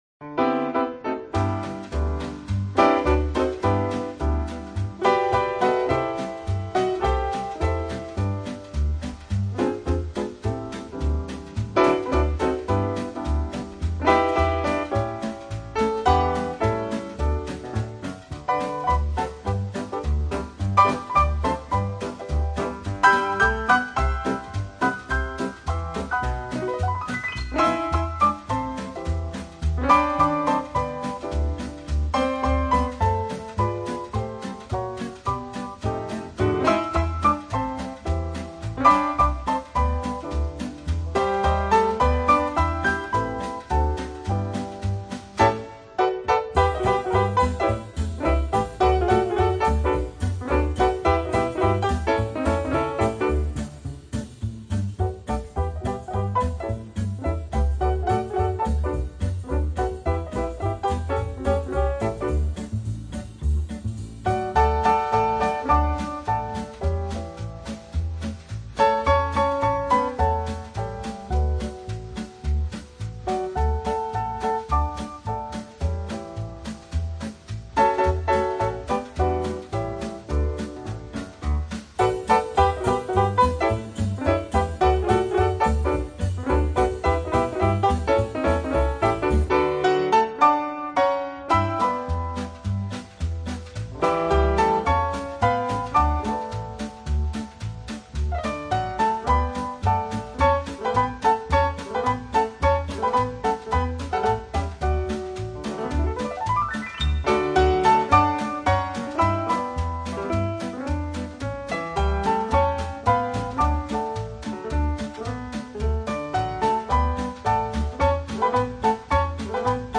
Жанр: Easy Listening, Piano